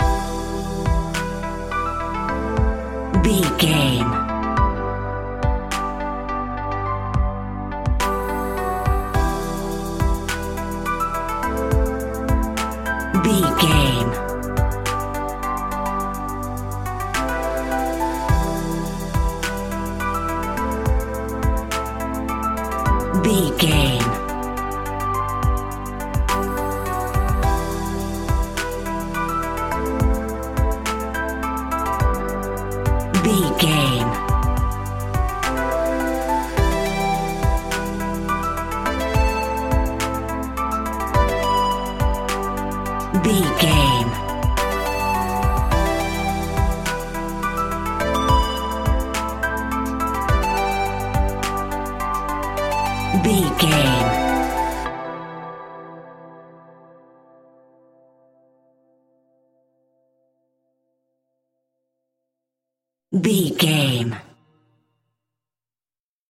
Aeolian/Minor
hip hop
chilled
laid back
groove
hip hop drums
hip hop synths
piano
hip hop pads